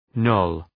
Shkrimi fonetik {nəʋl}